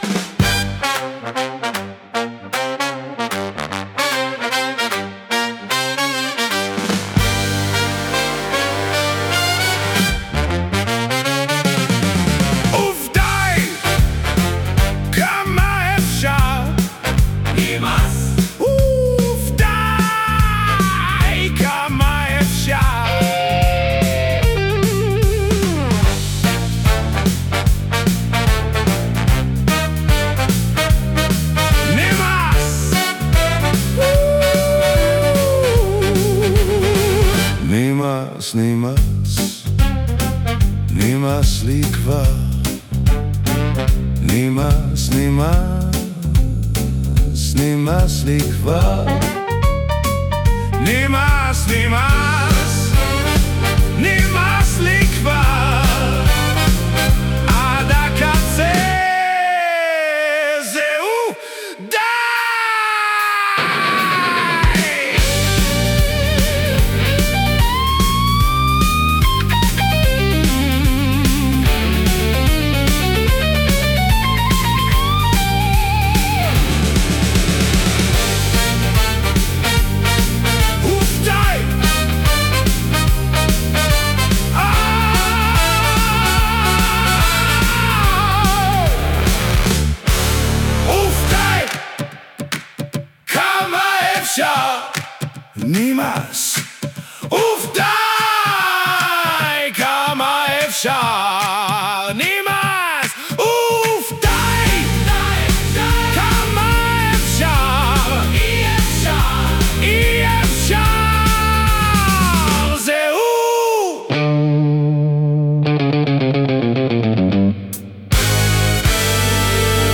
נ.ב.2 בכוונה יצרתי את זה בשירת גברים, שיהיה אפשר לשים את זה בפווווול ווליום!